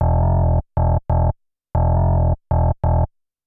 ROARING BA-R.wav